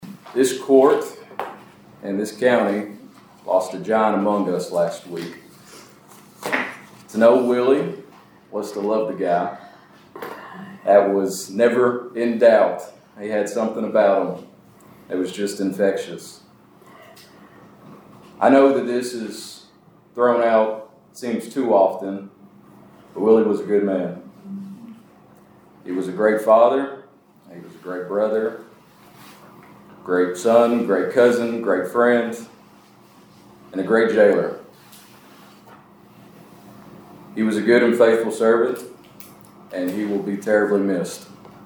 The Caldwell County Fiscal Court paused its Tuesday session to honor the late Jailer Willie Harper, who was remembered fondly for his dedication, financial stewardship, and ever-present smile following his courageous battle with cancer.
During the judge-executive comments, Kota Young offered his reflections on Harper.